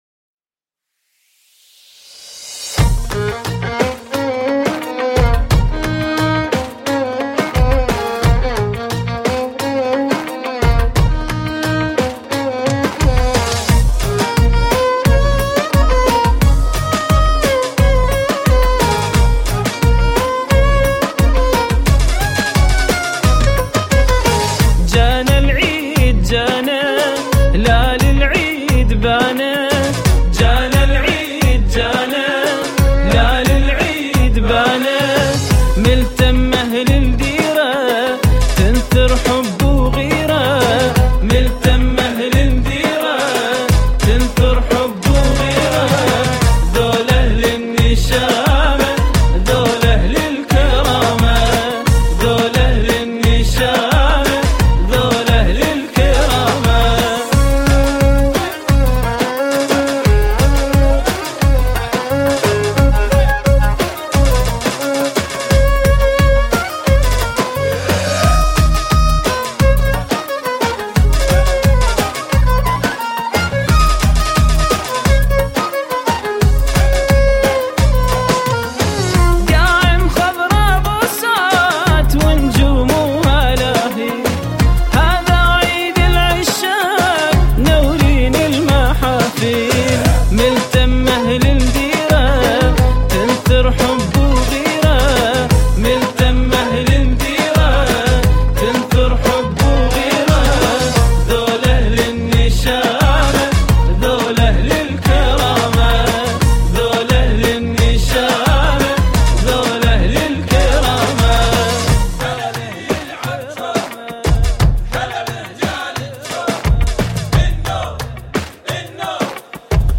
قطعه